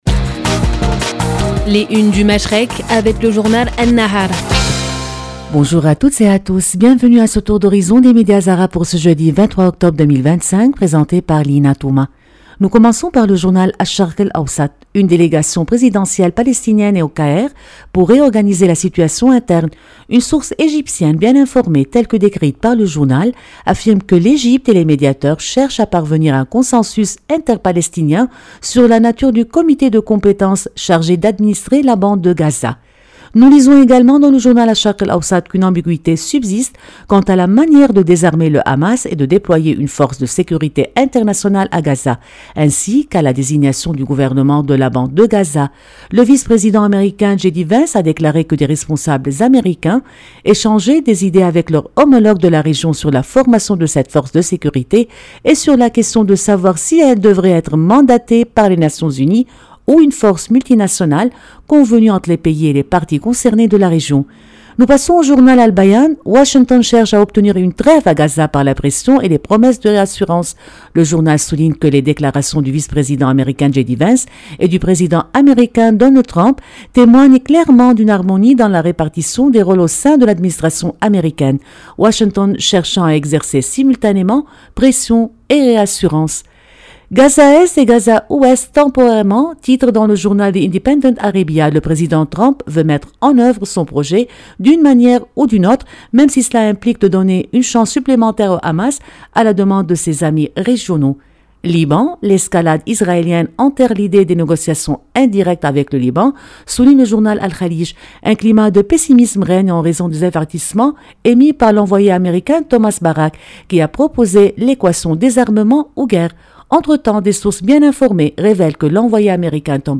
Revue de presse des médias arabes